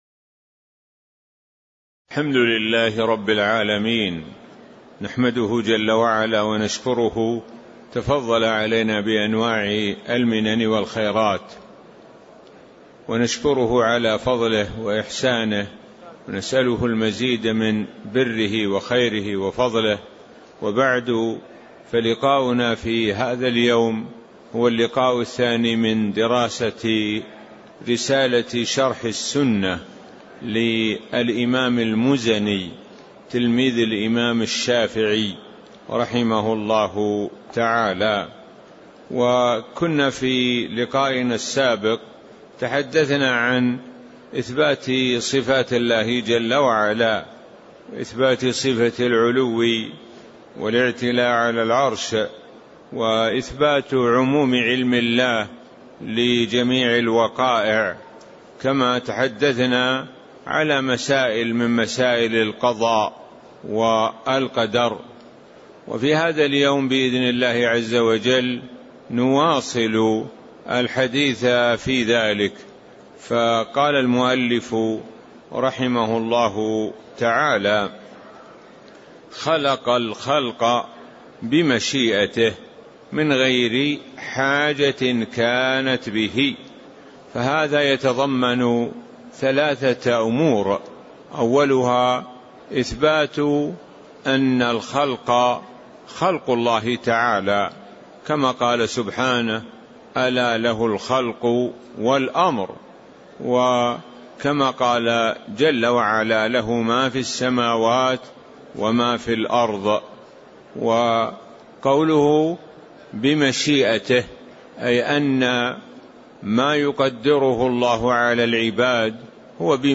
تاريخ النشر ١٧ ذو القعدة ١٤٤٣ هـ المكان: المسجد النبوي الشيخ: معالي الشيخ د. سعد بن ناصر الشثري معالي الشيخ د. سعد بن ناصر الشثري قوله: خلق الخلق بمشيئته (03)